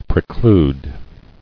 [pre·clude]